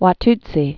(wä-ttsē) also Wa·tu·si (wä-tsē)